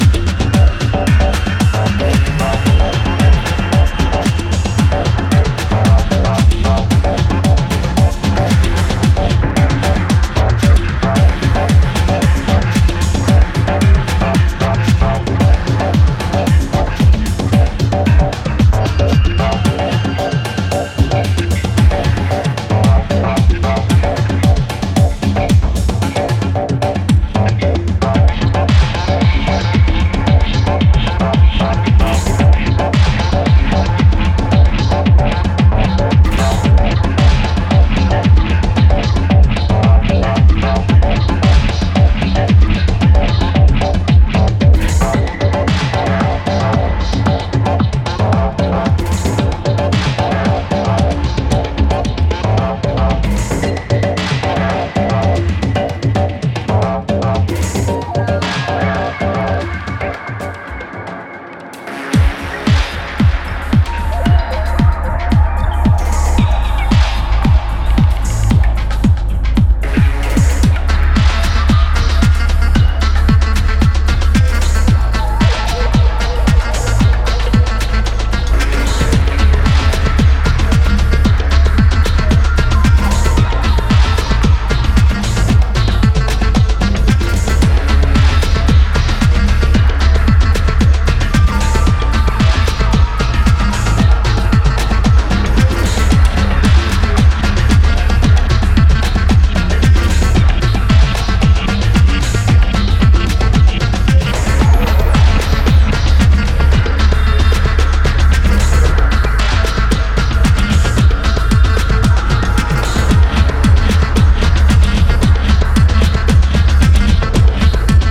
過去10年間にベルリンのクラブフロアで培われた経験が存分に発揮されている、非常に退廃的な内容ですね。